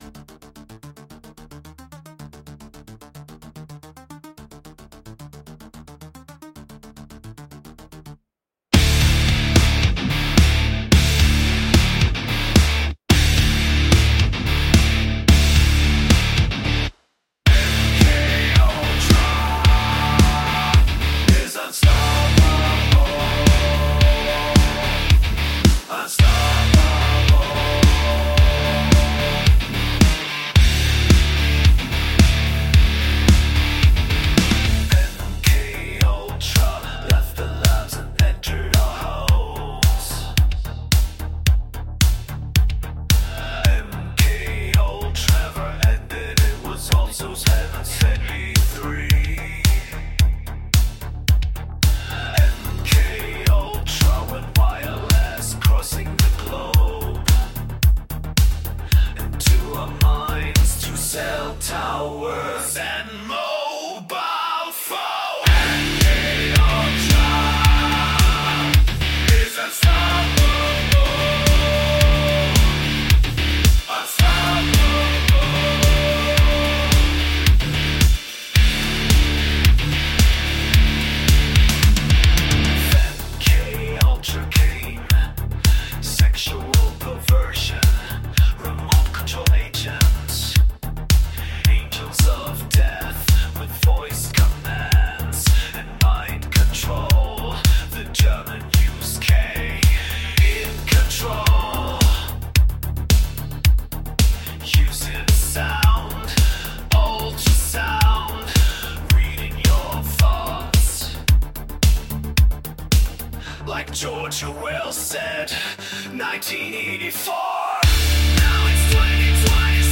HEAVY METAL VERSION LYRICS